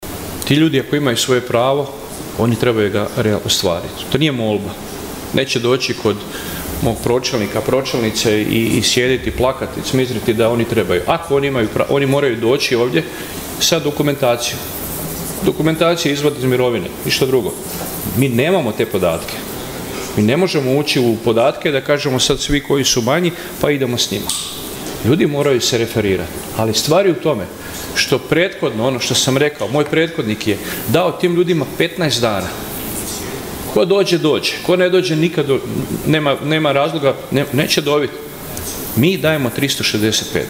Aktualni sat 6. sjednice Gradskog vijeća Grada Nova Gradiška i ovoga je puta bio prilika gradskim vijećnicima da postave pitanja vezana uz svakodnevno funkcioniranje grada i rad gradske uprave. Na sva postavljena pitanja odgovarao je gradonačelnik Vinko Grgić.